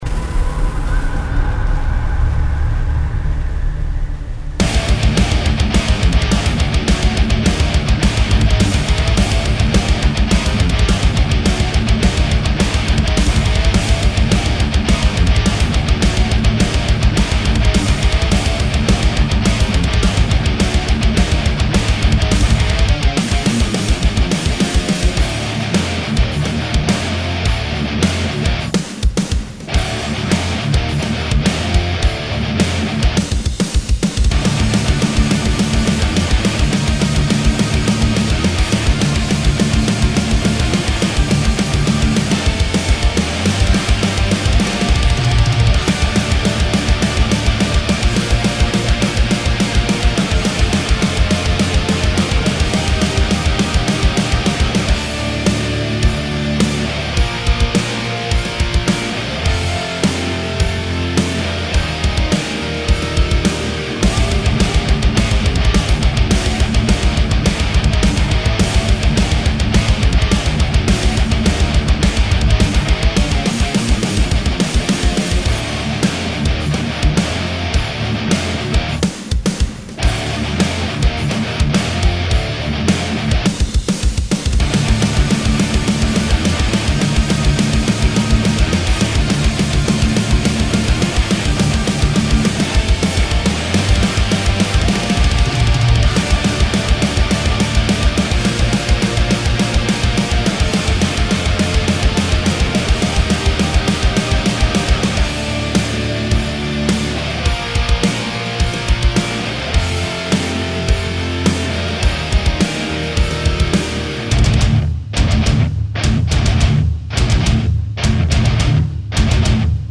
Trashcore?
(Fehlt halt noch Vocals und diverse Gitarren. ) Was haltet ihr von dem Song & Sound bis jetzt? Ich habs versucht soweit schon mal zu mischen. danke euch fürs hören :)